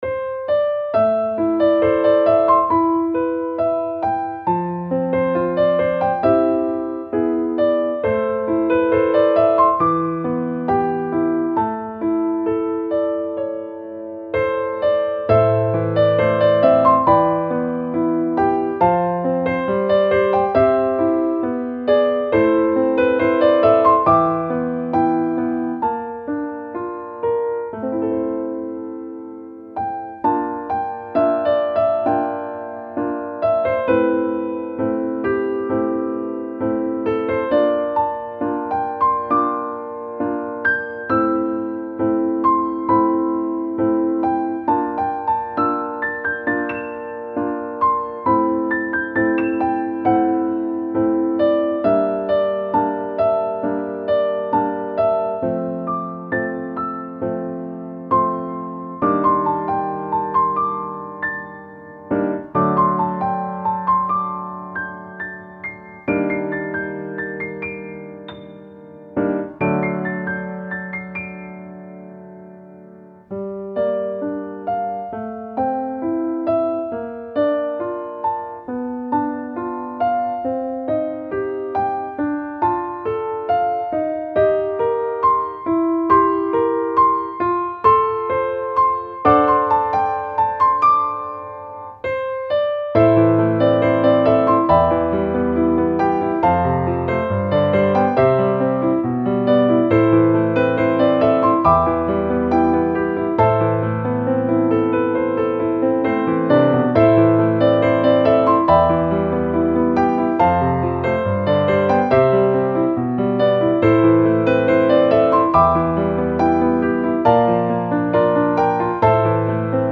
-oggをループ化-   ぬくもり しっとり 2:37 mp3